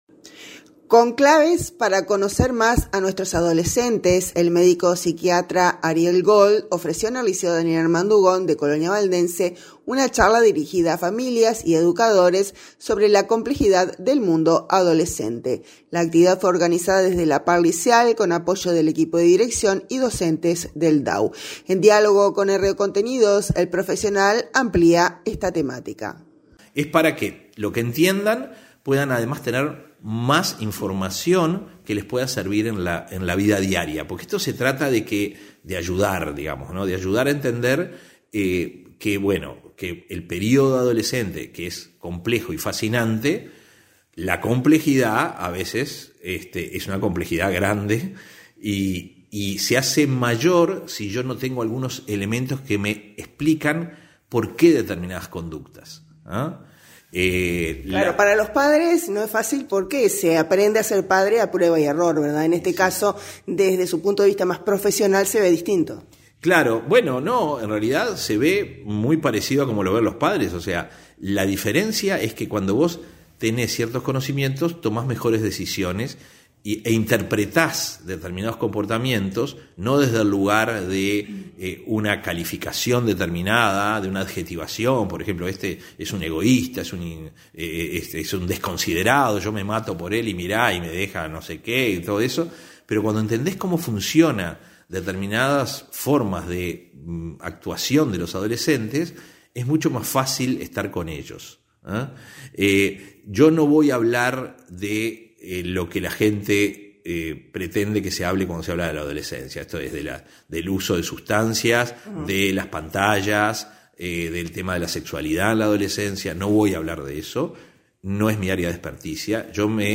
En diálogo con RO Contenidos, el profesional se refiere a estas temáticas.